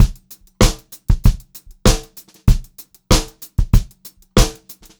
96POPBEAT3-L.wav